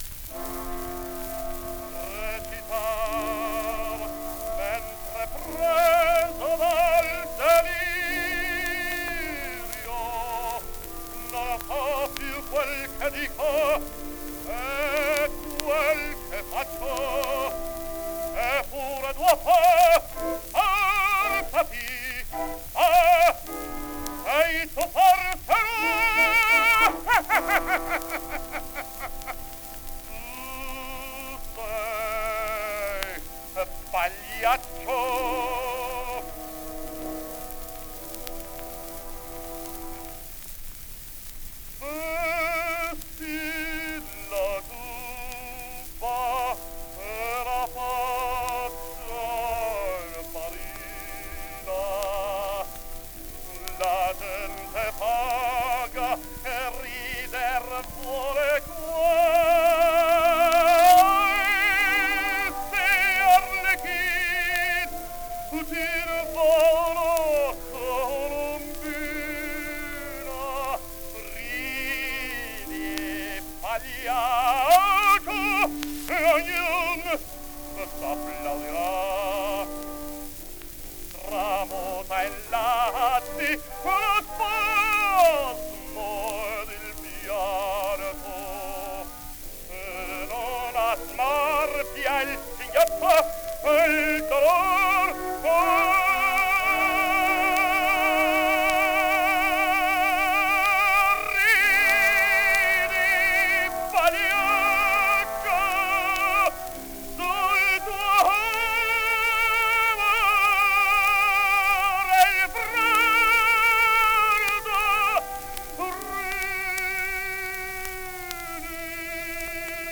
Index of /publications/papers/dafx-babe2/media/restored_recordings/caruso_giubba